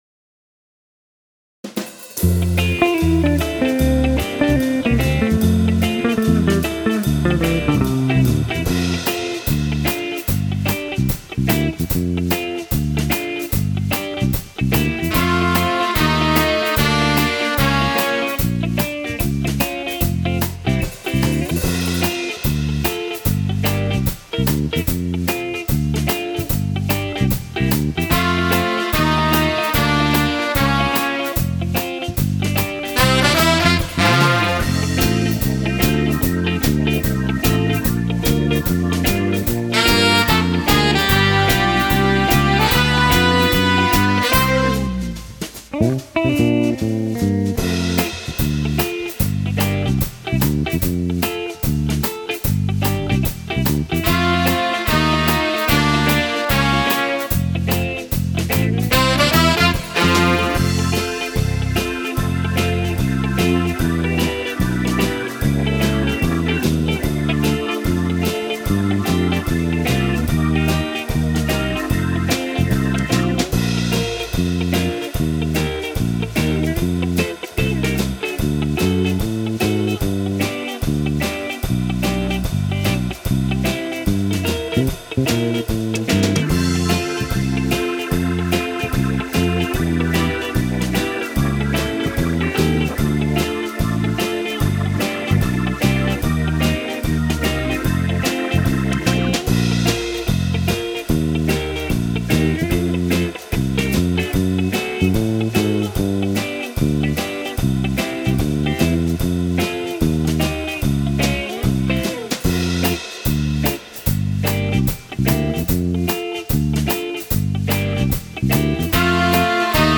Moderato swing
Fisarmonica